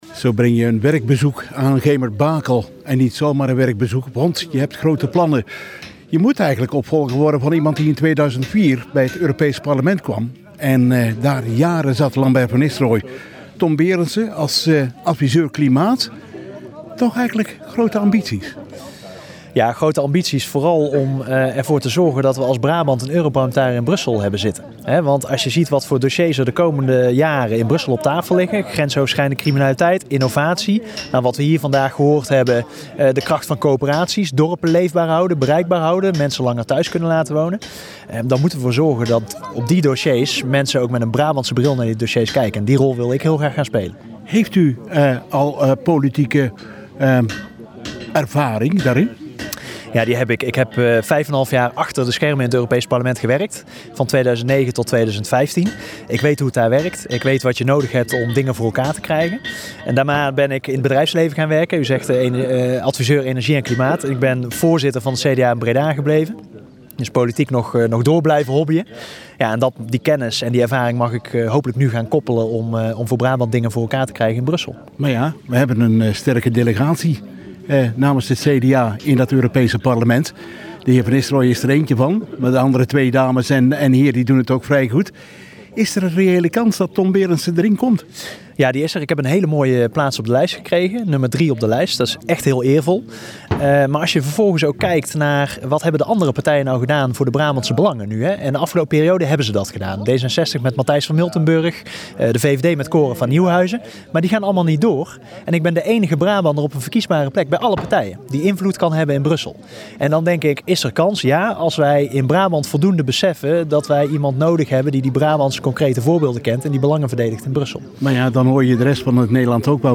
Het bezoek werd afgesloten met opnames voor de lokale Omroep Centraal.
Interview met Tom Berendsen.